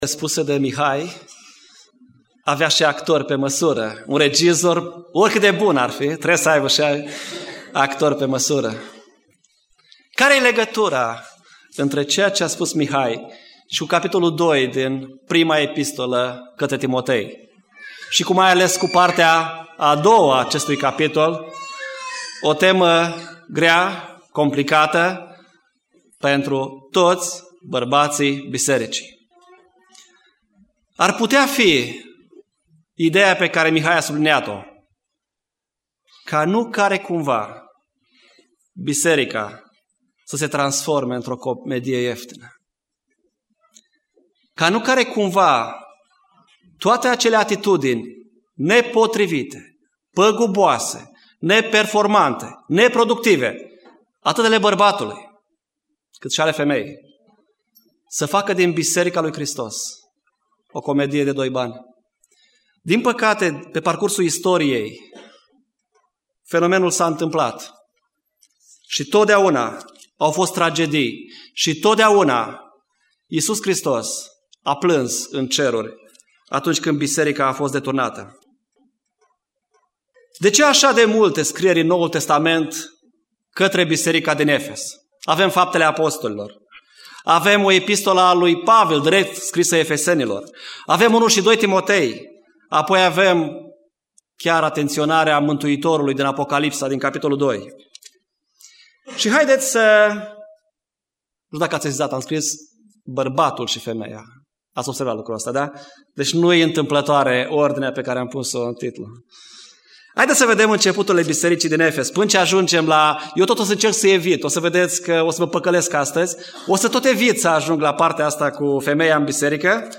Predica Exegeza 1 Timotei 2:8-15